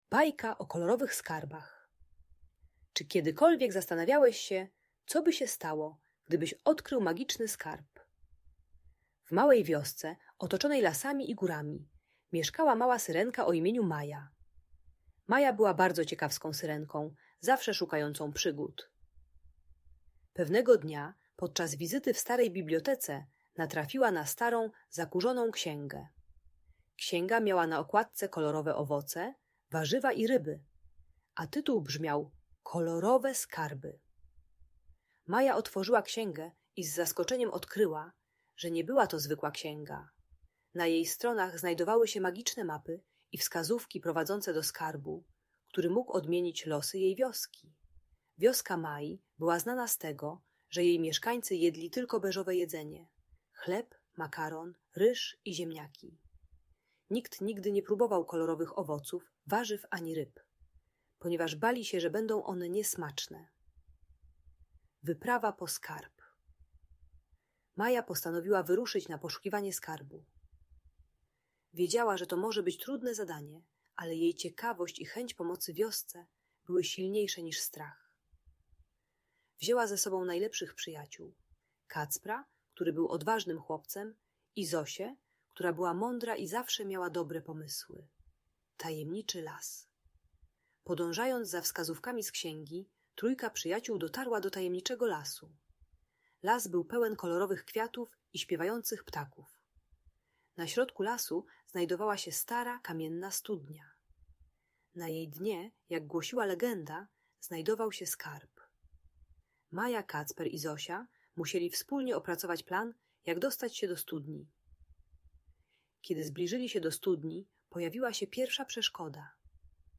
Bajka o Kolorowych Skarbach - Audiobajka dla dzieci